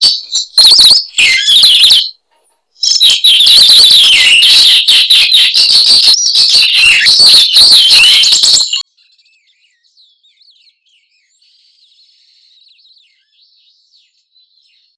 Lophospingus pusillus - Soldadito